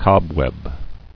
[cob·web]